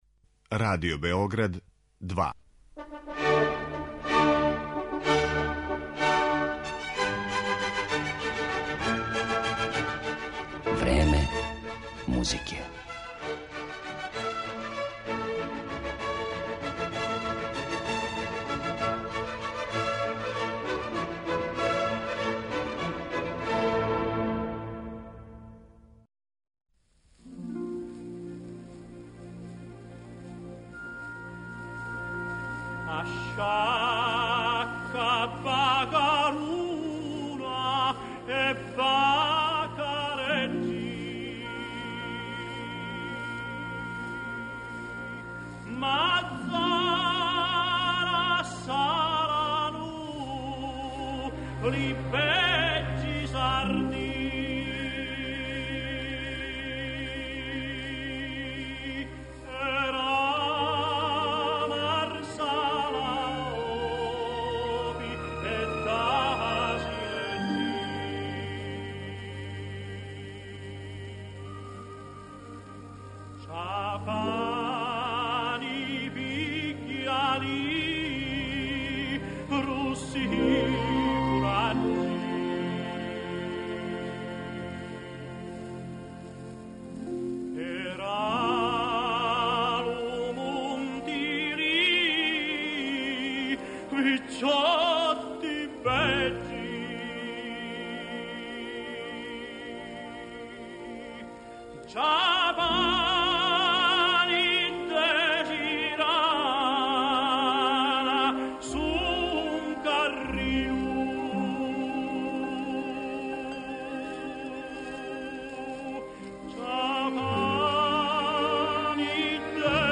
Портрет овог уметника осликаће арије из најпознатијих опера Пучинија, Вердија, Маснеа...